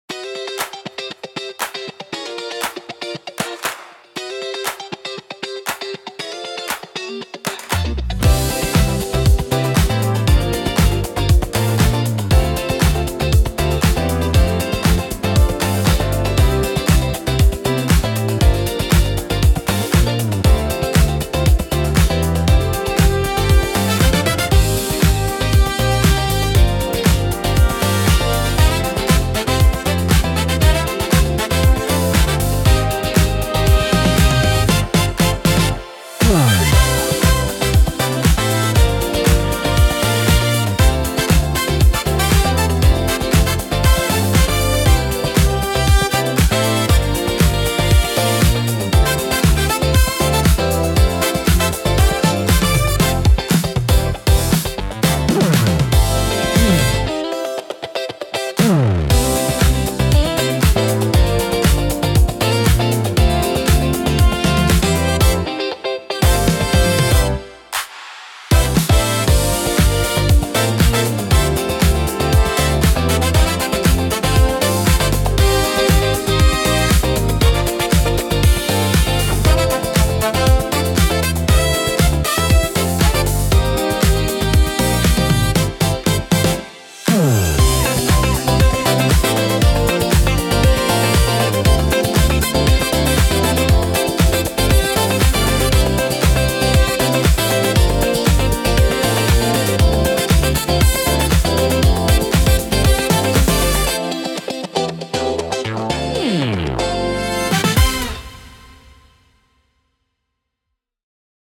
Listen to all the brass production music tracks